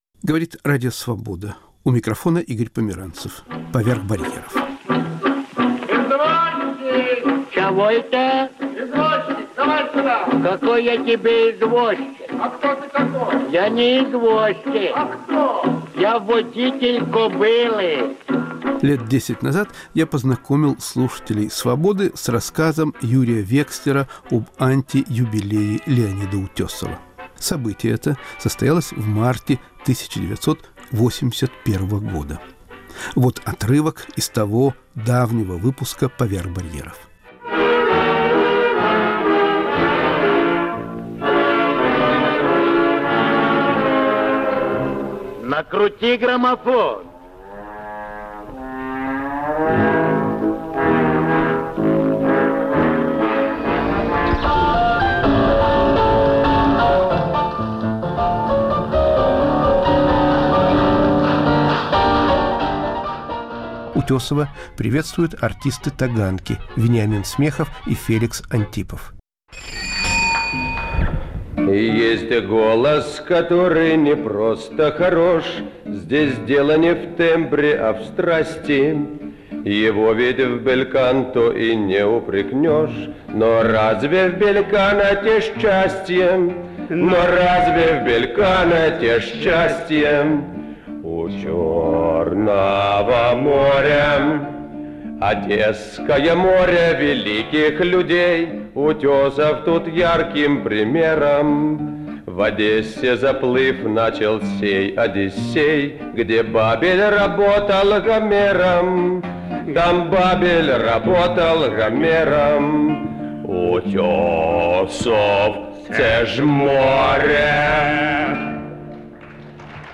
Друзья отмечают 86-летие Л. Утёсова.